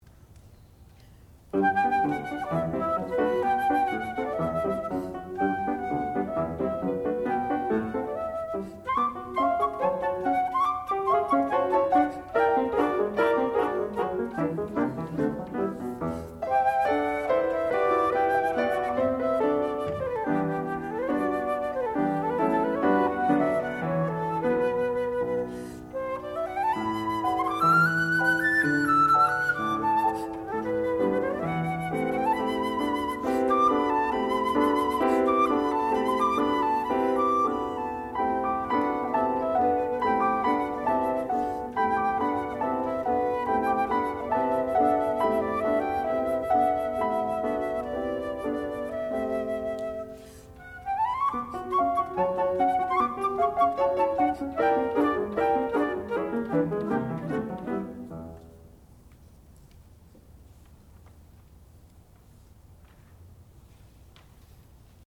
classical music
piano
flute
Advanced Recital